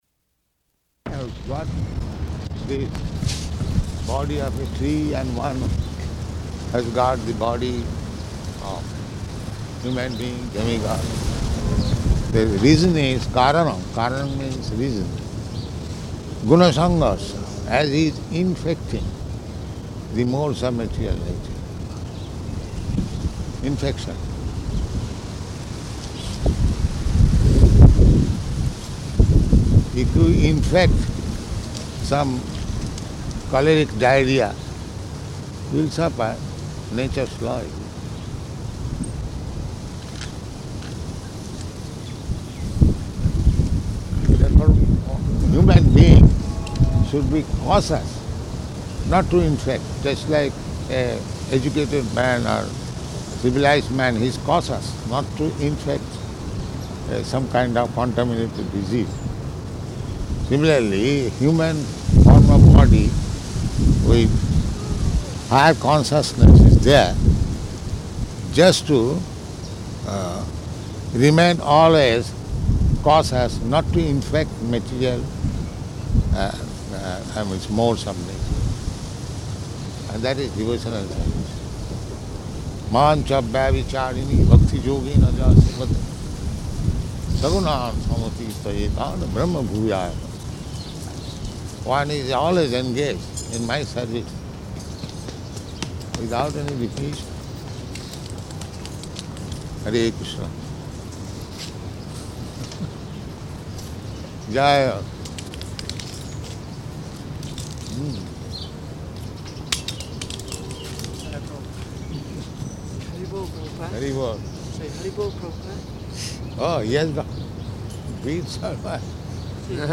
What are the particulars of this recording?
Morning Walk, partially recorded Location: Melbourne